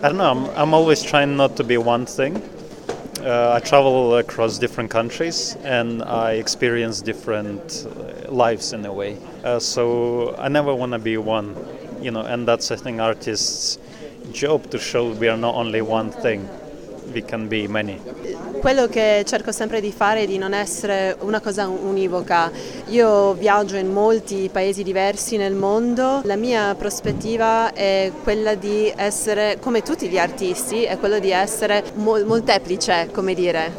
Al microfono della nostra corrispondente